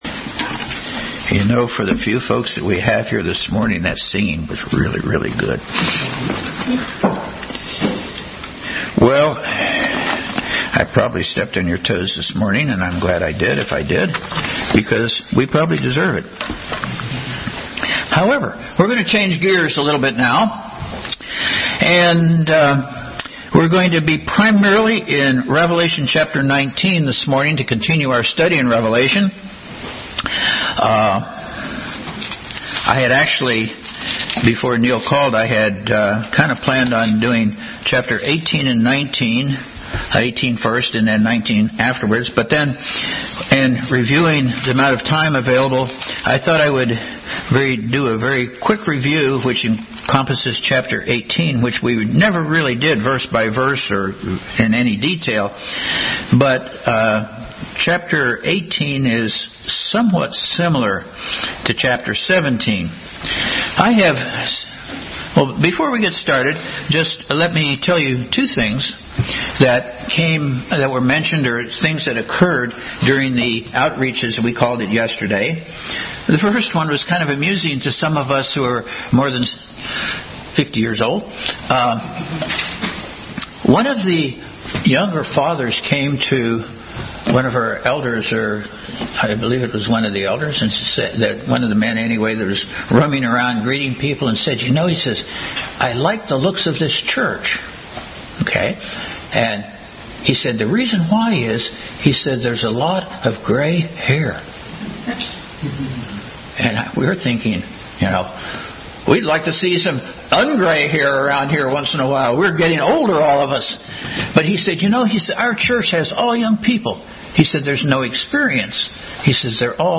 Bible Text: Revelation 19:1-21, 17:16-17, Hebrews 10:17, 2:12, Proverbs 27:1, James 5:1, 2 Corinthians 11:1-3 | Family Bible Hour message.